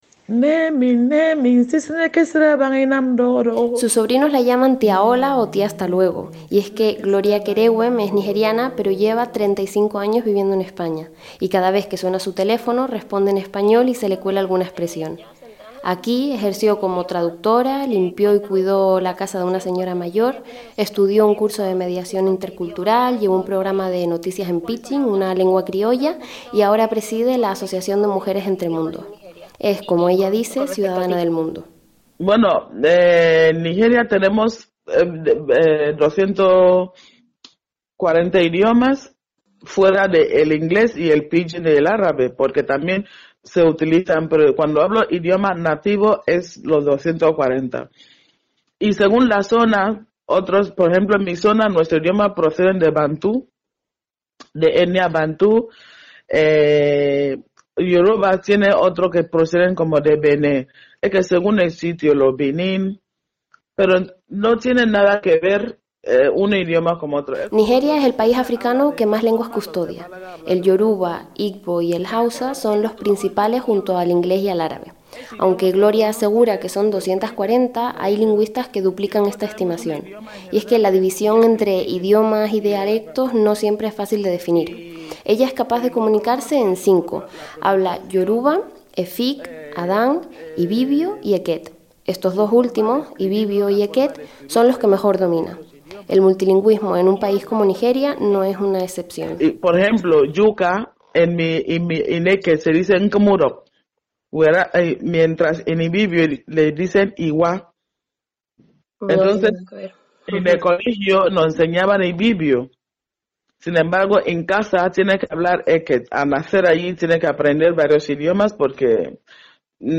Ocho migrantes de la región occidental del continente realizan un recorrido sonoro por sus lenguas nativas y narran aquí en ‘podcasts’ su lucha cotidiana por mantenerlas vivas desde la distancia y la añoranza de sus países de origen.